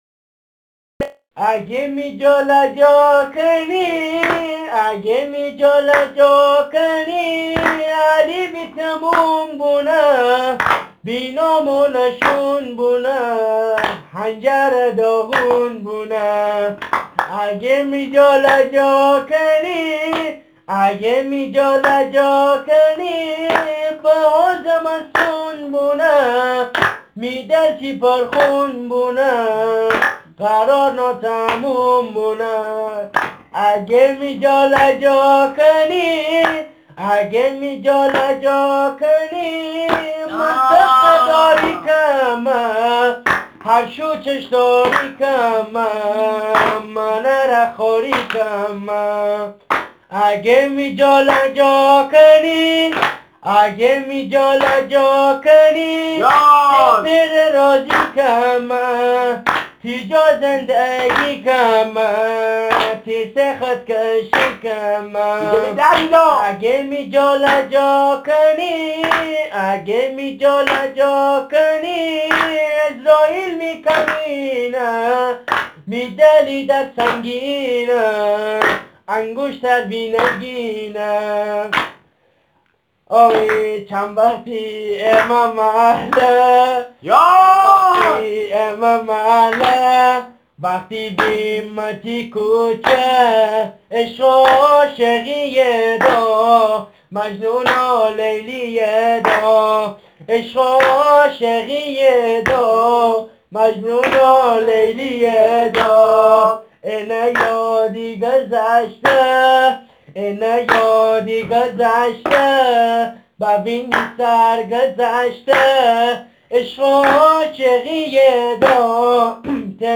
دانلود آهنگ جدید تک دست
مازندرانی تک دست
آهنگ شاد